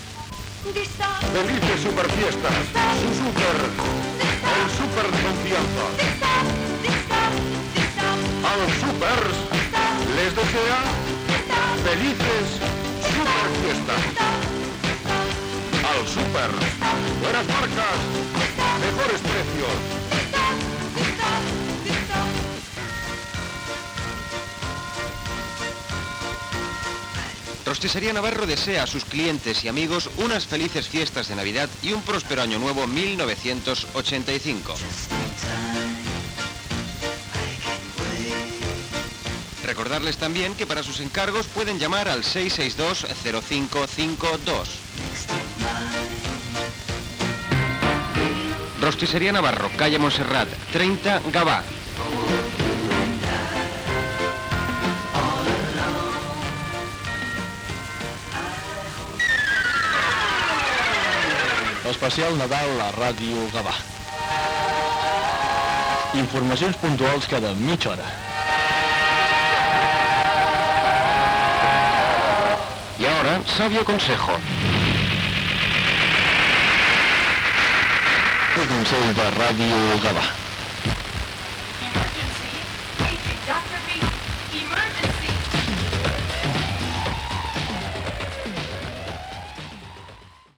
a740c705d1c6c51baf5f7e9b0cd7de991732ee7d.mp3 Títol Ràdio Gavà Emissora Ràdio Gavà Titularitat Pública municipal Descripció Publicitat, identificació especial Nadal.